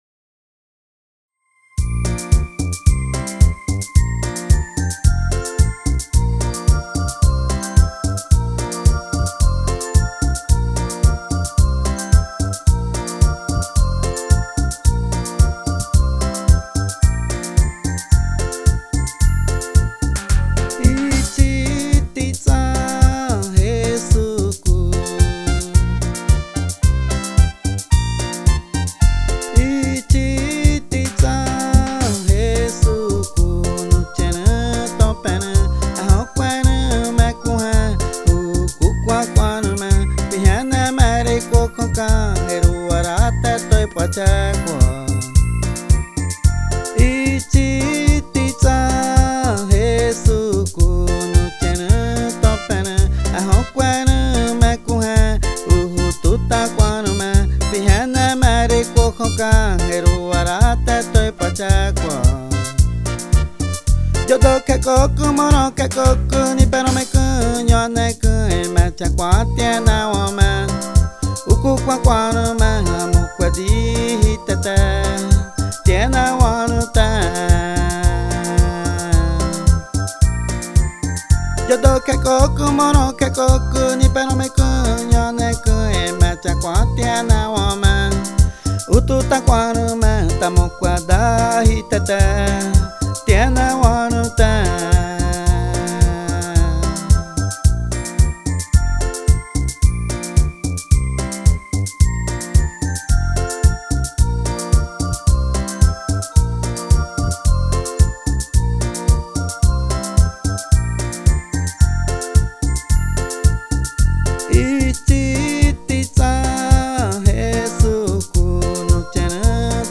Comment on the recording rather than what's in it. Today, I received a video call from the group of P “sound engineers” at their new studio.